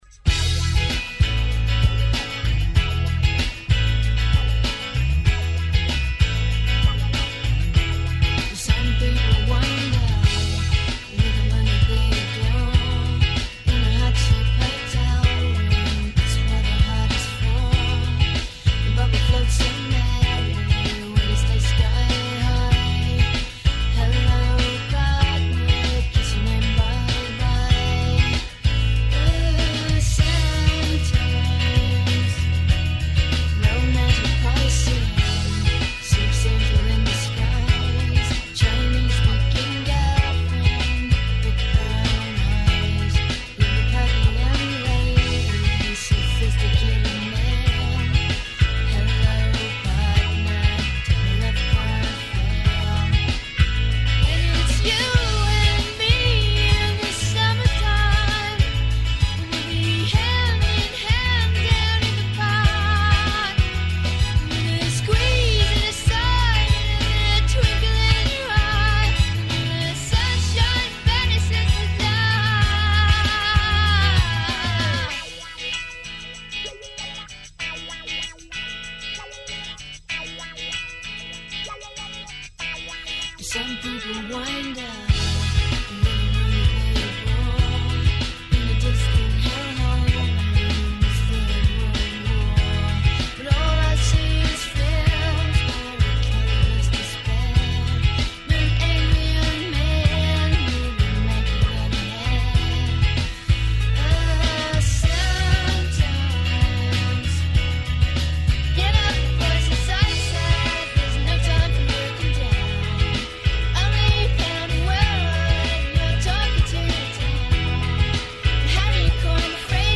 Гости су нам два млада бенда – Синестезија и Ничим изазван.
Најавићемо њихове наступе током лета, учешћа на фестивалима, а слушаћемо и њихове најновије песме.